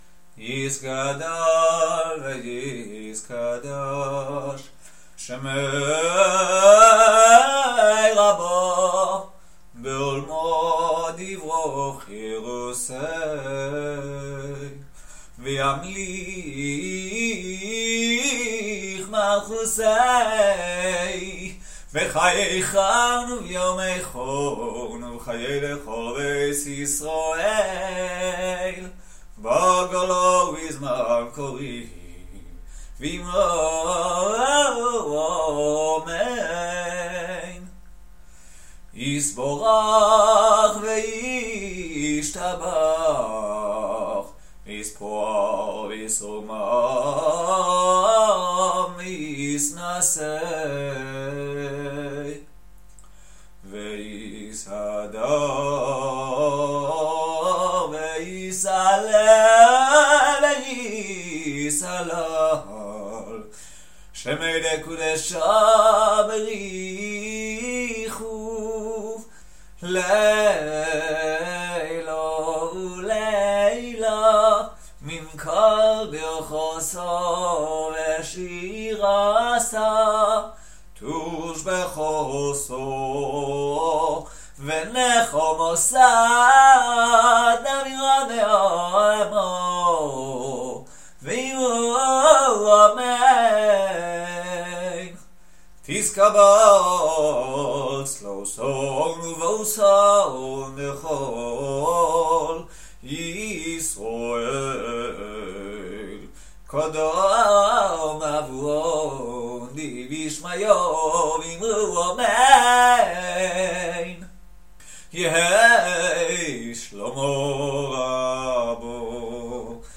Kaddish Tiskabal:: Shemini Atzeres: Selichos Medley.mp3